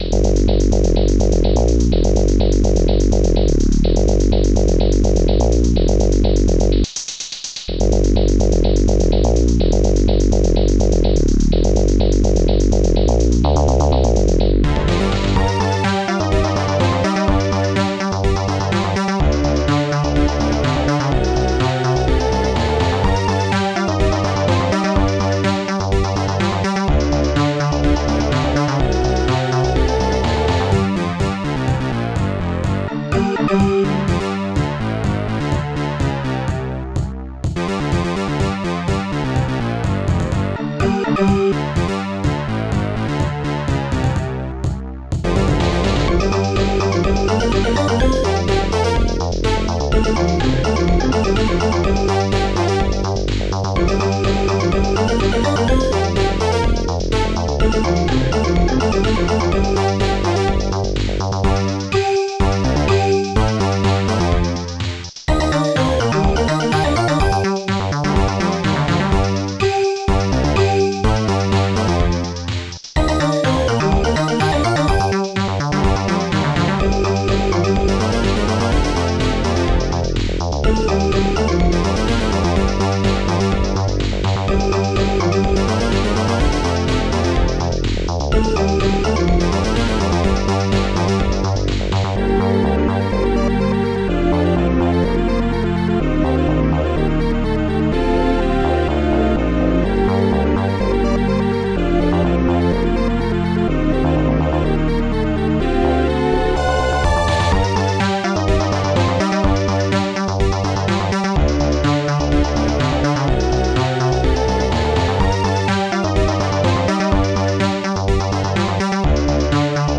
strings1 monobass bassdrum3 popsnare2 hihat2 squares hallbrass strings6 touch